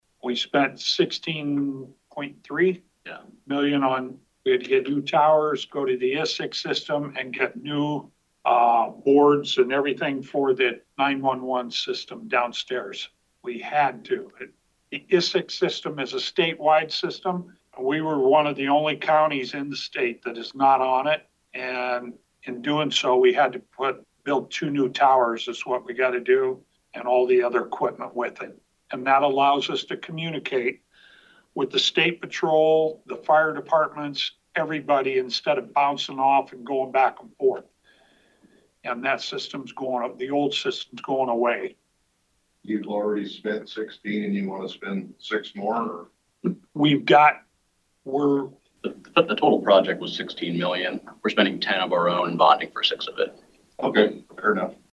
(Pottawattamie Co.) This morning, the Pottawattamie County Board of Supervisors held a public hearing to consider authorizing a loan agreement and issuing General Obligation Capital Loan Notes in an amount not to exceed $6,000,000.
Supervisor Chair Brian Shea addressed a question during the hearing asking what this was for…